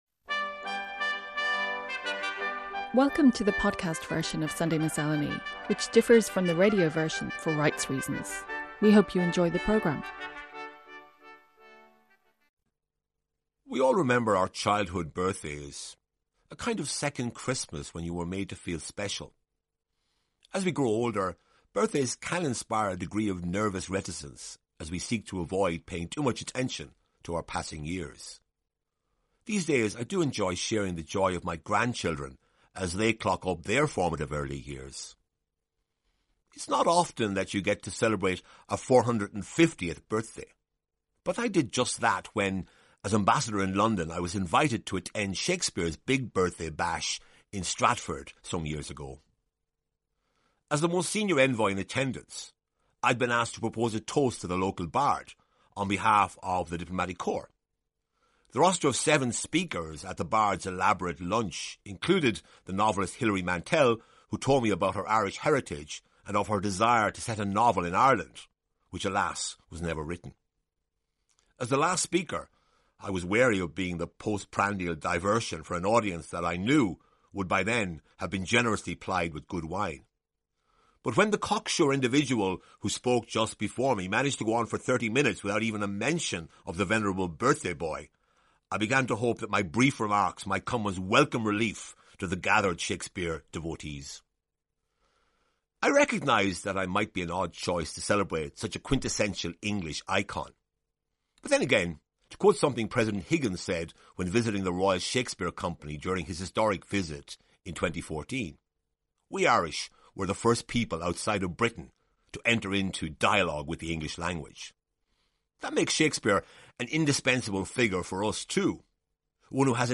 Radio essays, poems and complementary music, broadcast from Ireland on RTÉ Radio 1, Sunday mornings since 1968.